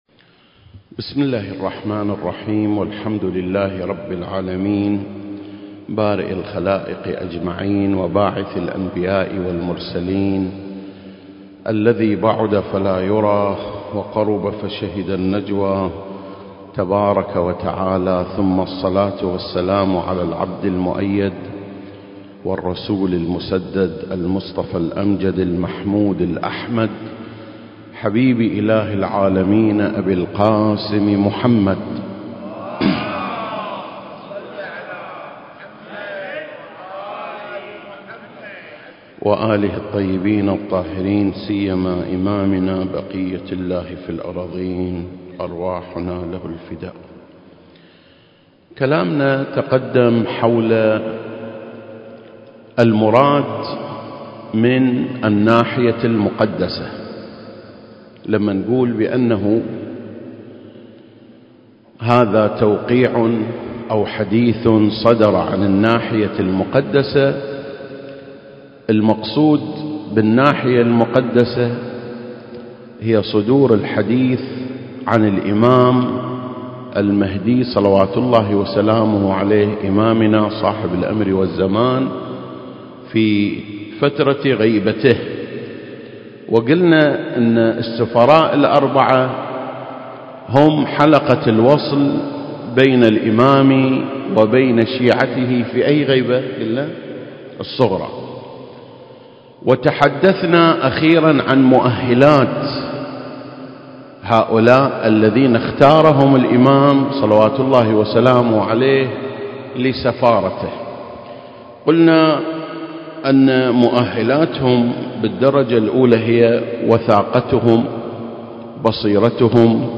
سلسلة: شرح زيارة آل ياسين (5) - الناحية المقدسة 2 المكان: مسجد مقامس - الكويت التاريخ: 2021